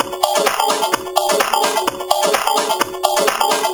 VEH1 Fx Loops 128 BPM
VEH1 FX Loop - 24.wav